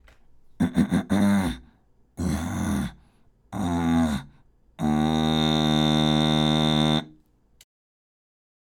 次に、「ん」の状態で咳払いして、ノイズを作り、その音を「ん」で保ちます。
※「ん」のノイズの見本音声
10_kaseitai_noise_nnN.mp3